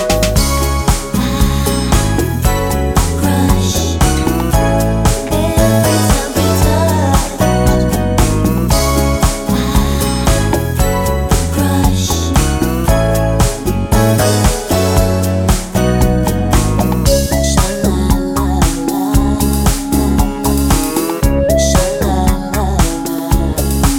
no intro BV Pop (1990s) 3:27 Buy £1.50